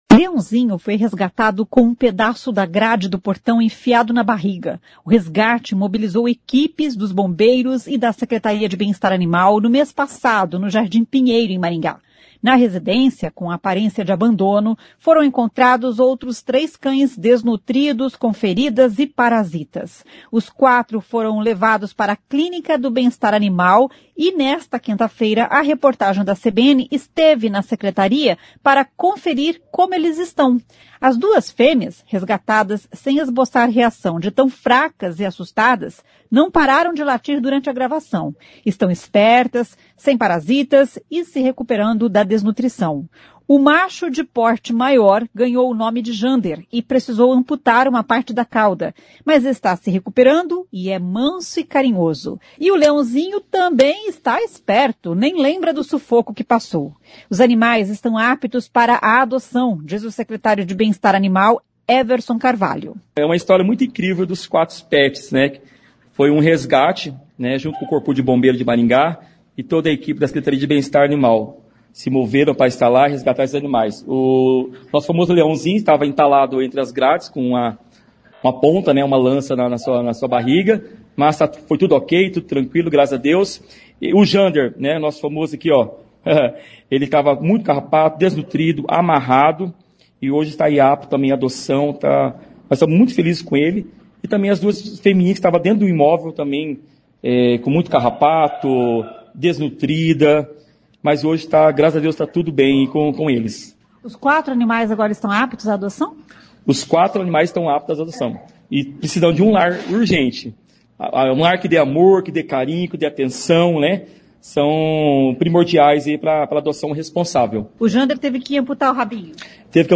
Os quatros foram levados para a clínica do Bem-Estar Animal e nesta quinta-feira (10) a reportagem da CBN esteve na secretaria para conferir como eles estão.
As duas fêmeas, resgatadas sem esboçar reação de tão fracas e assustadas, não pararam de latir durante a gravação.
Os animais estão aptos para a adoção, diz o secretário de Bem-Estar Animal Everson Carvalho.